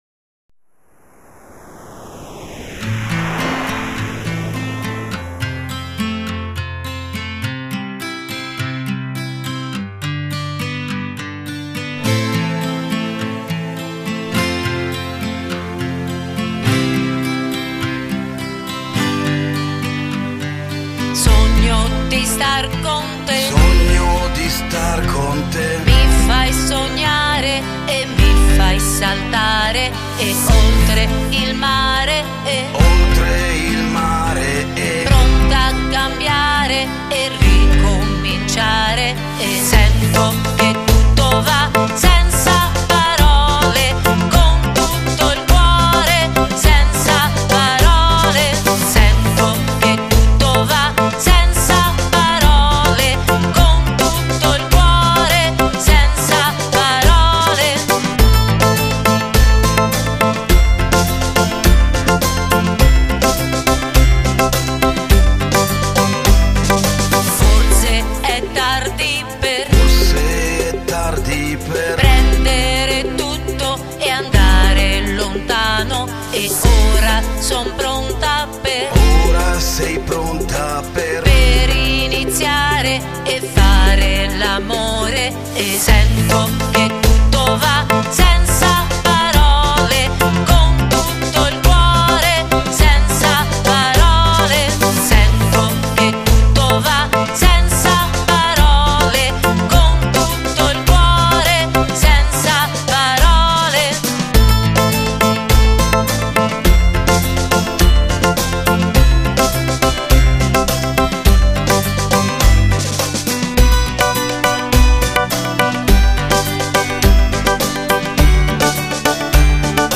acustica